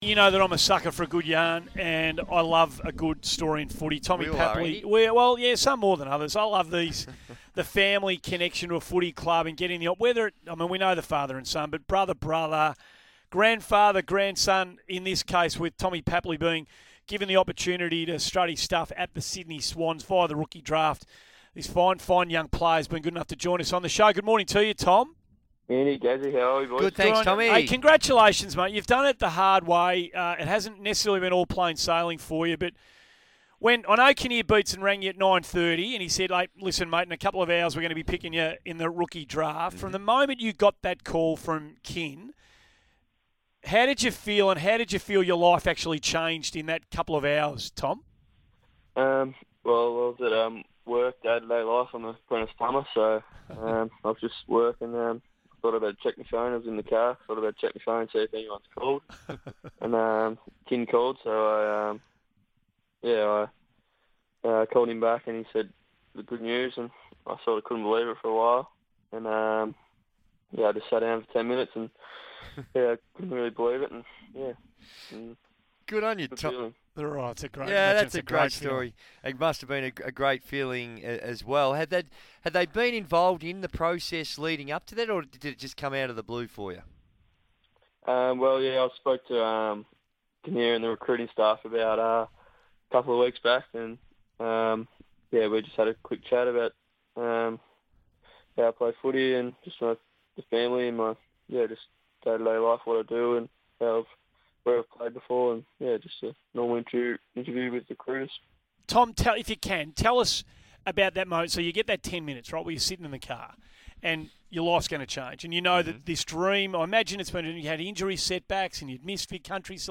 Tom Papley speaks to the Morning Glory team on SEN Radio.